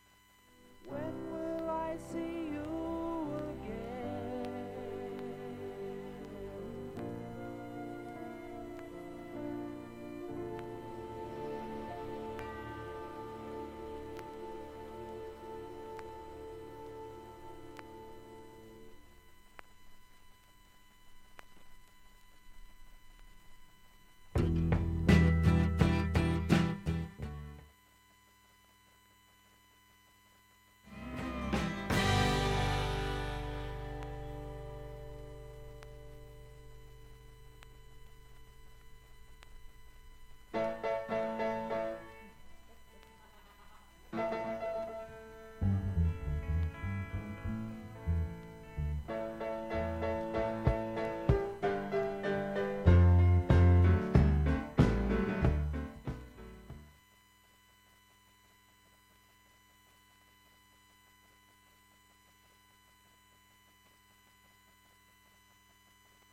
盤面きれいで、音質良好全曲試聴済み。
曲間無音部にごくかすかにプツが聴こえるのみで
ほかA-1にかすかな3回のプツが1箇所。
ほか単発のかすかなプツが2箇所。